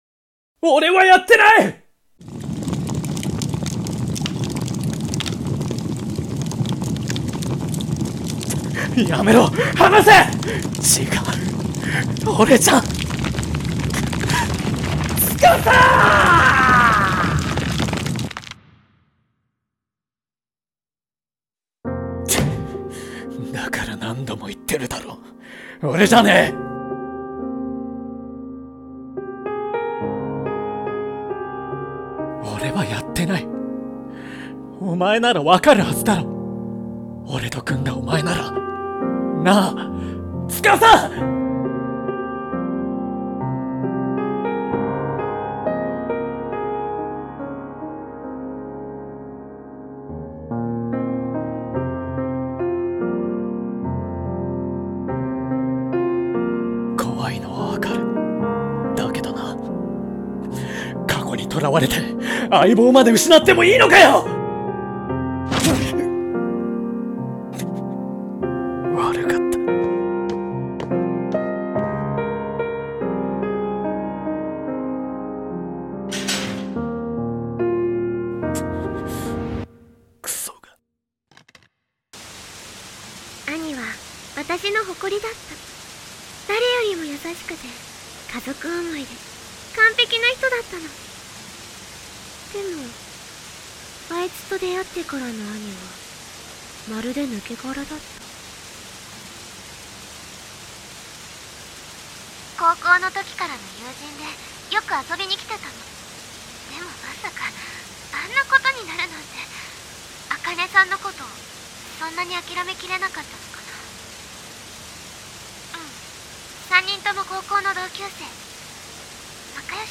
【4人用声劇】